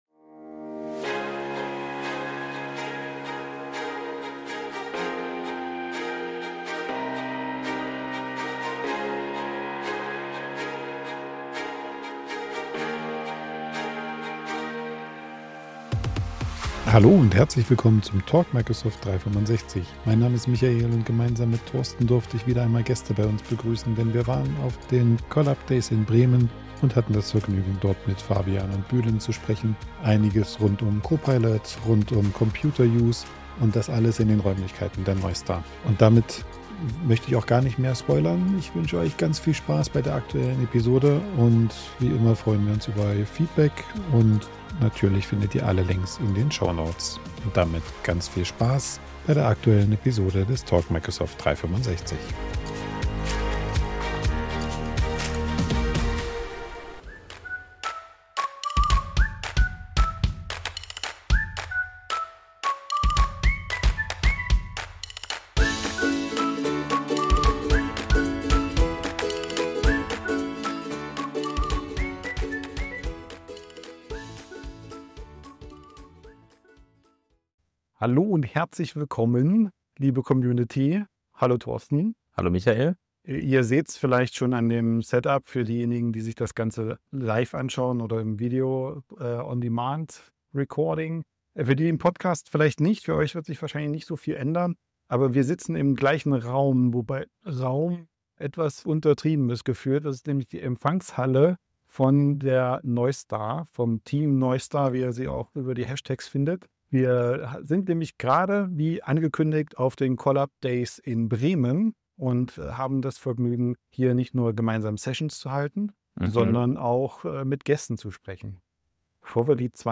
Live-Podcast von den CollabDays in Bremen